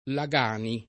[ la g# ni ]